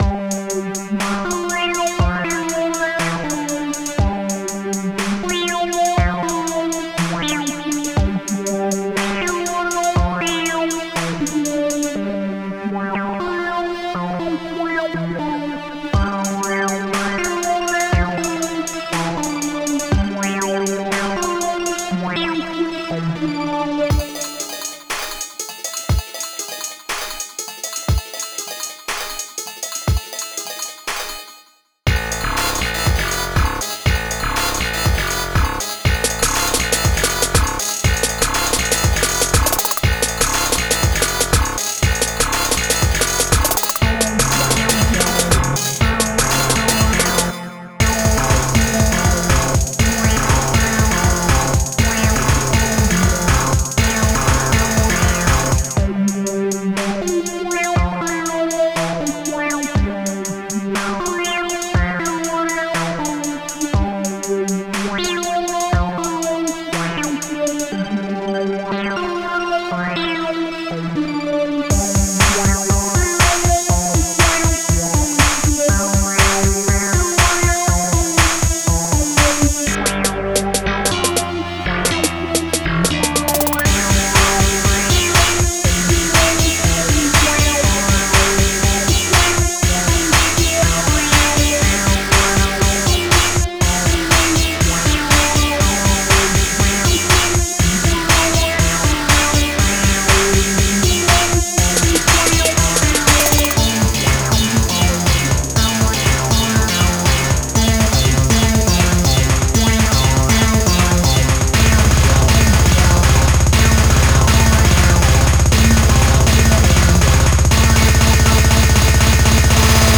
weird, electronica, surreal, experimental,